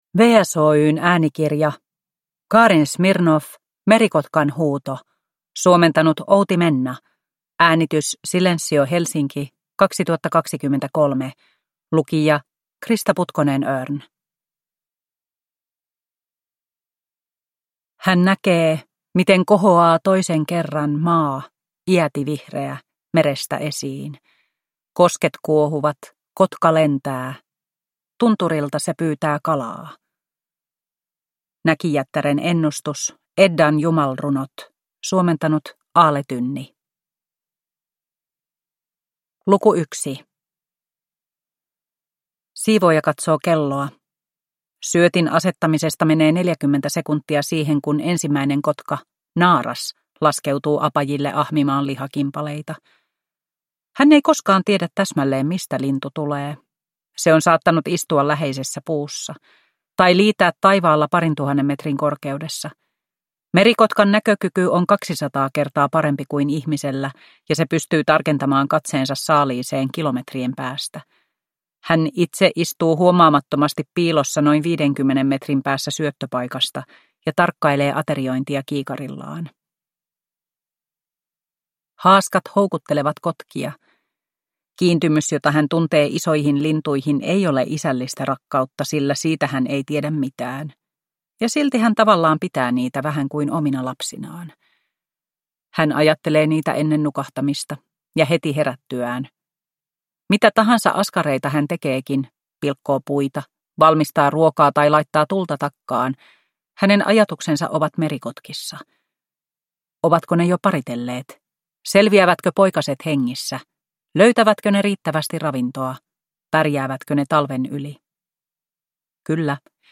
Downloadable Audiobook
Ljudbok
Narrator